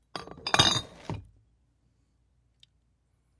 近身肉搏战 " 室内酒杯碰碰杯 各种各样的
标签： 厨房 葡萄酒 叮当声 sounddesign 器皿 效果 室内 玻璃 SFX 拟音 声音
声道立体声